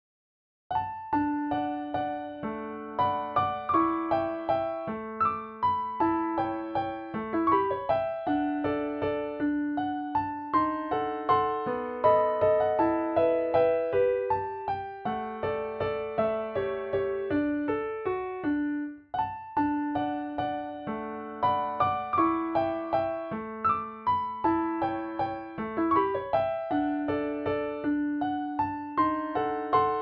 D Major
Waltz